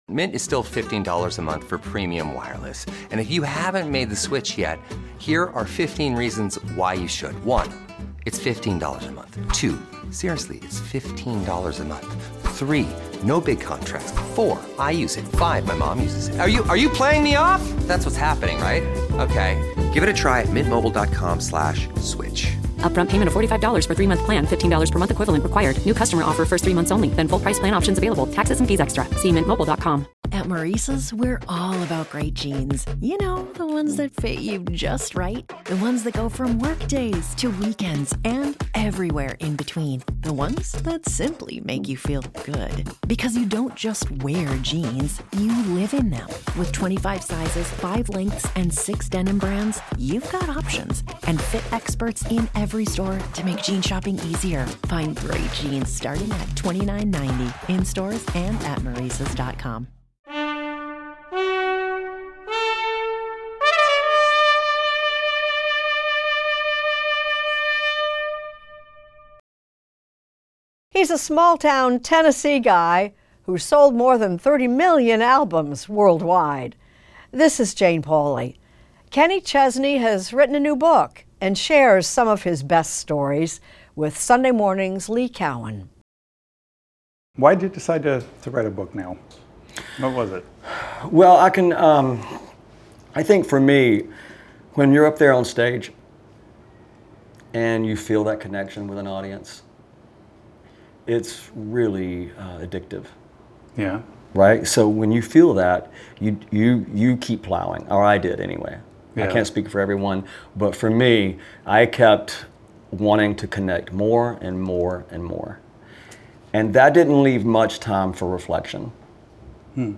Extended Interview: Kenny Chesney
In this exclusive, the country superstar talks with Lee Cowan about his new book, "Heart Life Music."